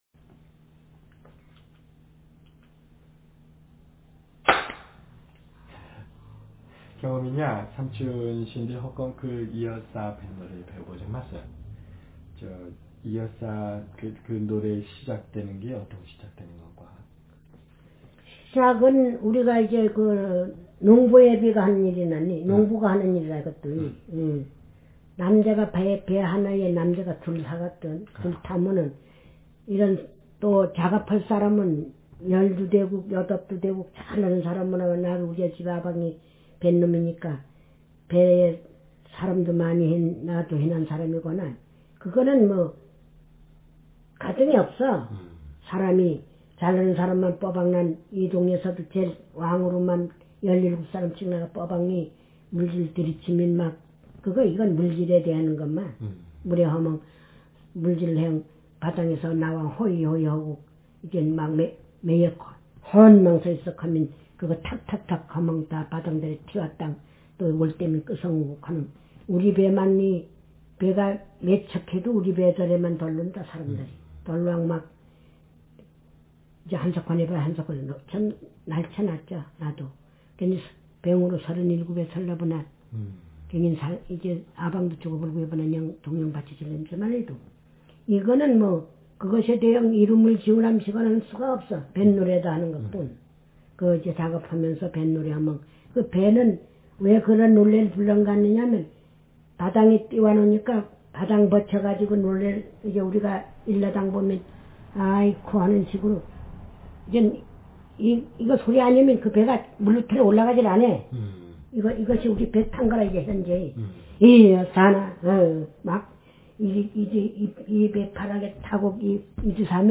Speaker sexf
Text genreconversation